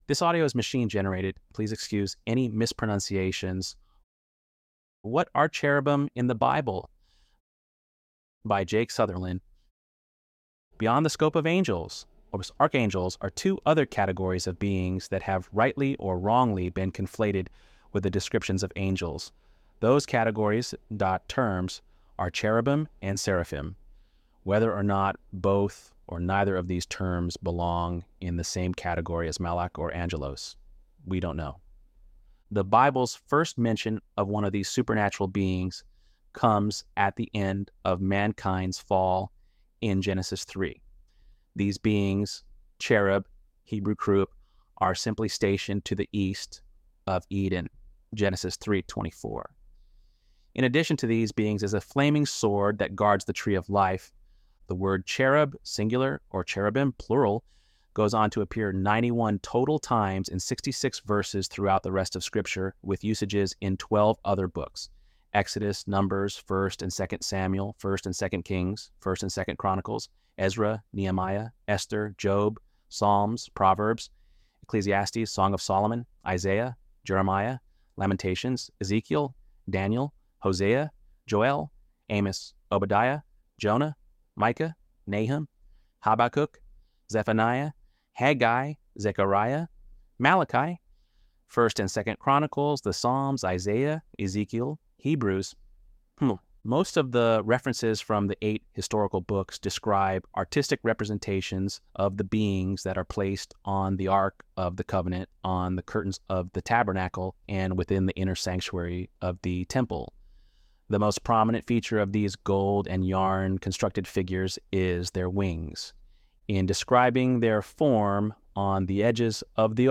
ElevenLabs_12_16.mp3